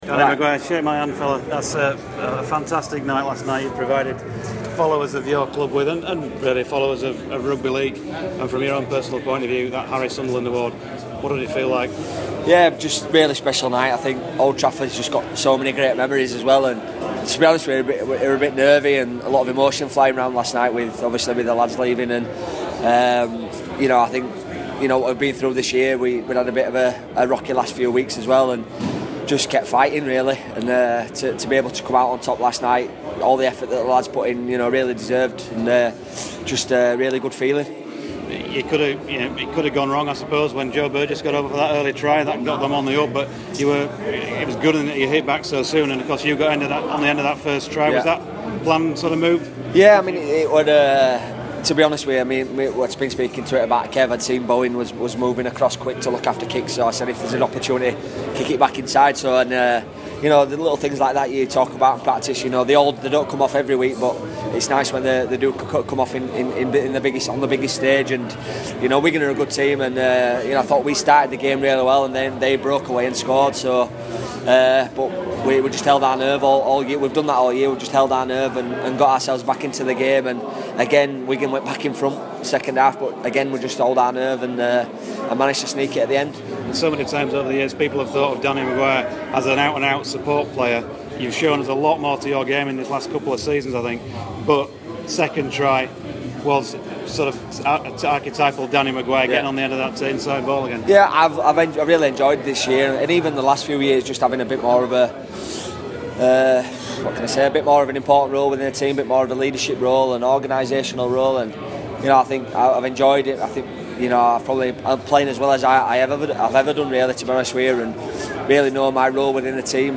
at Leeds Rhino's Grand final celebrations